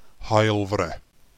To hear how to pronounce Haulfre, press play: